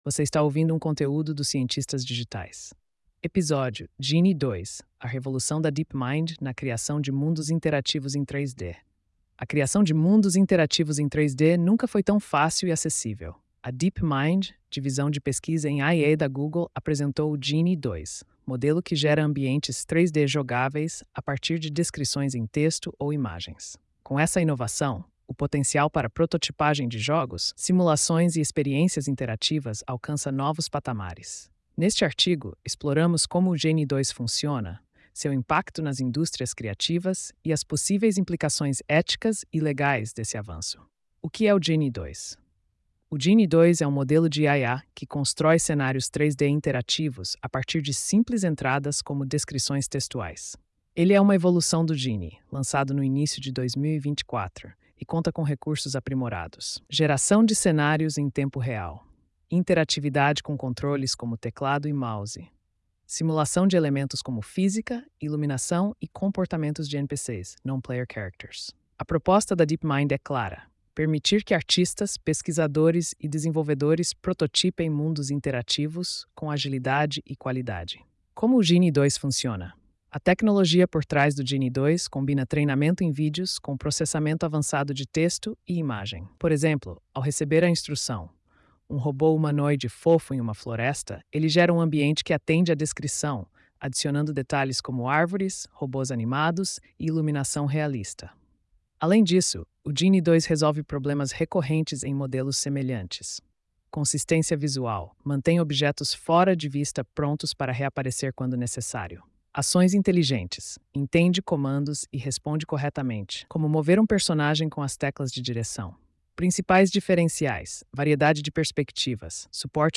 post-2647-tts.mp3